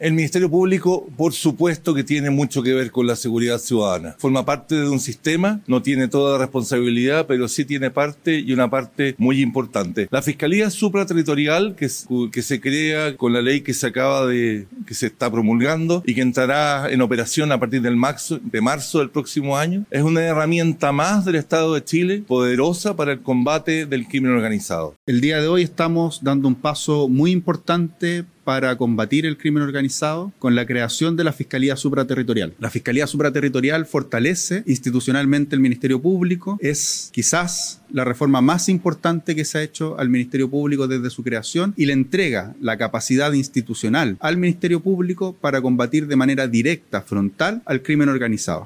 El fiscal nacional Ángel Valencia valoró la promulgación de la norma y aseguró que representa “un nuevo capítulo para el Ministerio Público”, mientras que el ministro de Justicia, Jaime Gajardo, sostuvo que esta reforma busca que el Estado “esté un paso adelante” en la lucha contra las organizaciones criminales.